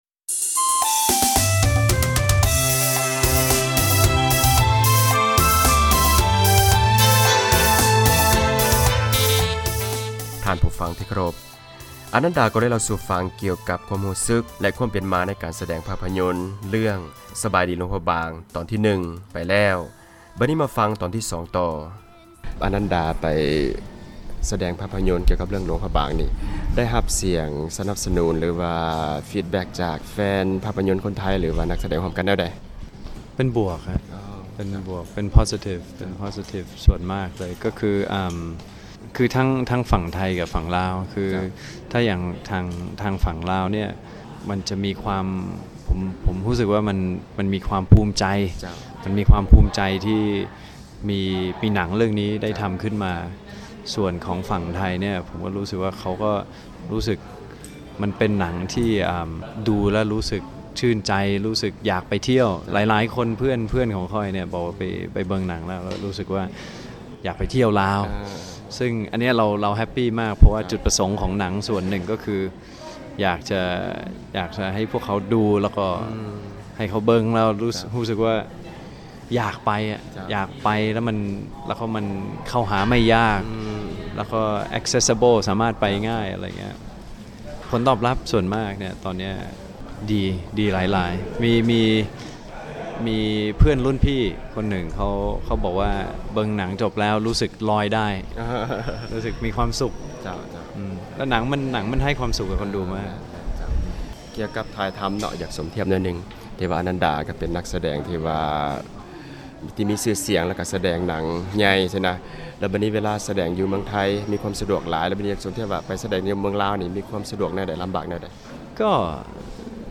ສັມພາດ ອານັນດາ ເອເວີຣິງແຮມ ກ່ຽວກັບ ການສະແດງ ພາພຍົນ ເຣື້ອງ "ສະບາຍດີ ຫລວງພຣະບາງ"